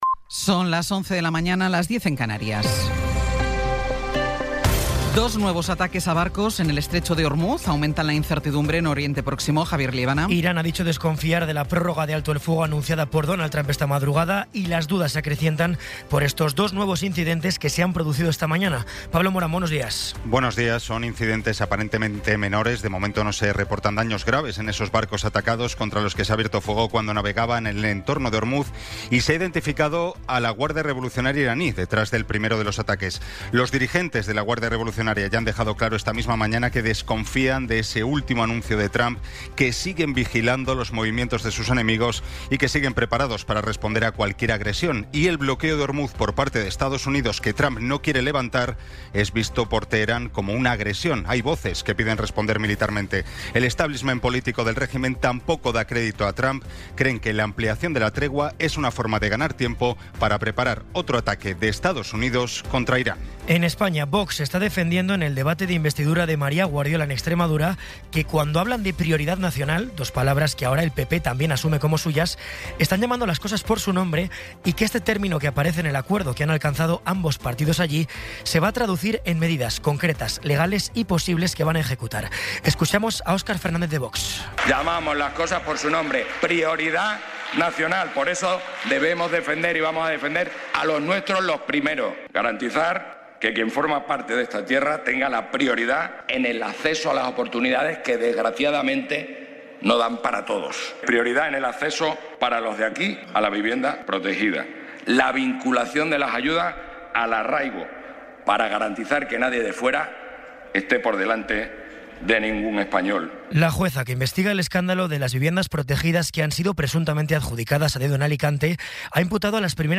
Resumen informativo con las noticias más destacadas del 22 de abril de 2026 a las once de la mañana.